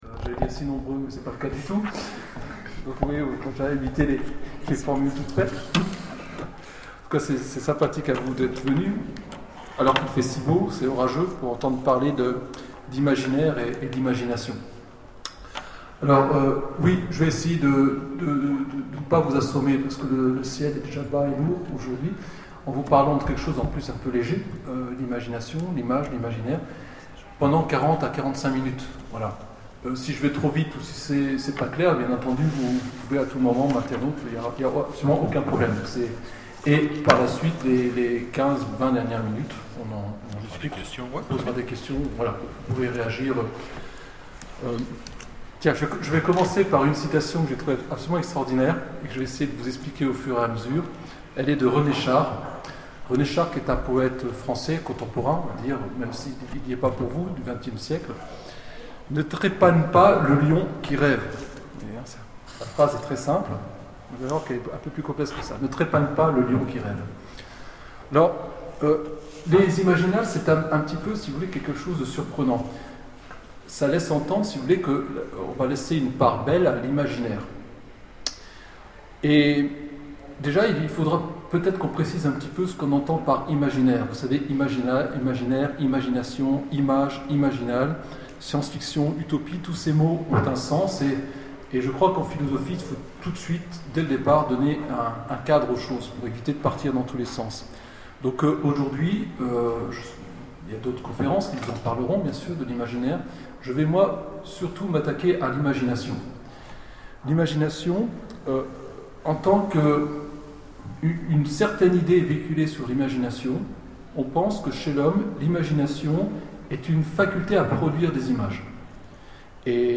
Imaginales 2012 : Conférence L'imaginaire et la fiction